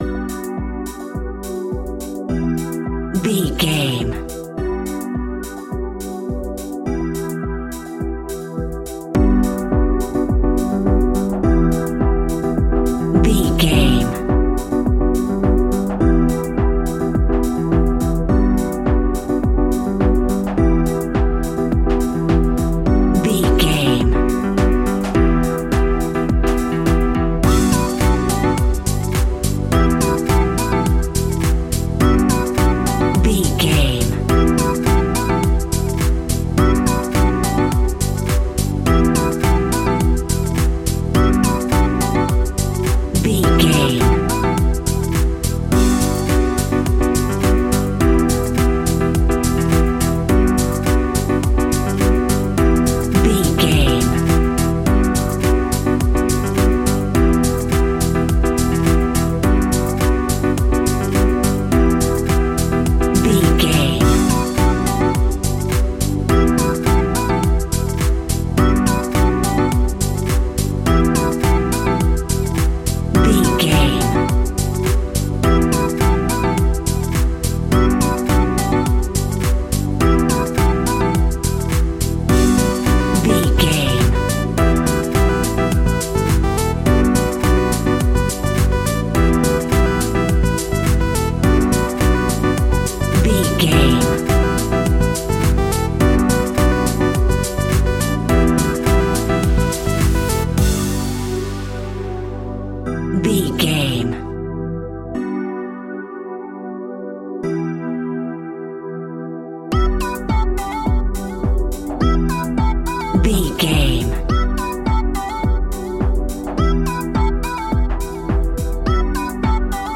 Aeolian/Minor
uplifting
futuristic
energetic
repetitive
bouncy
synthesiser
drum machine
electric piano
chillwave
synth leads
synth bass